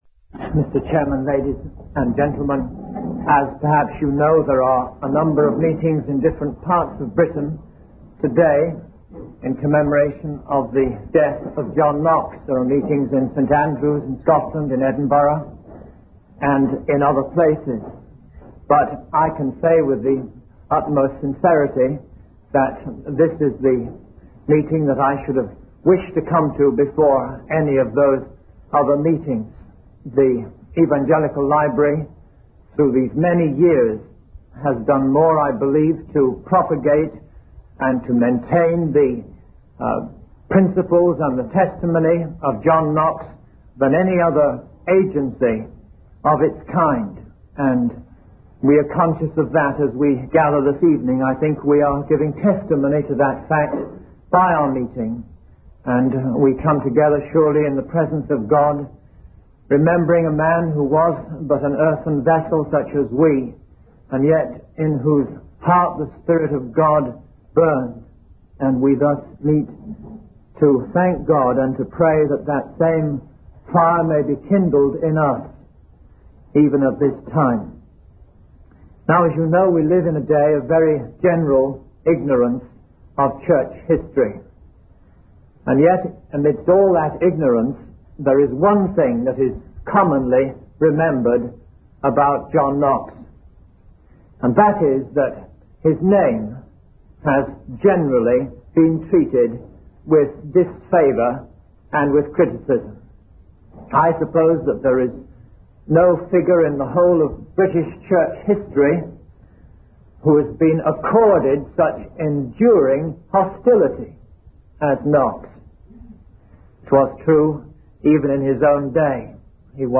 In this sermon, John Knox emphasizes the power and importance of God's scriptures in revealing His wrath and our need for salvation through Jesus Christ.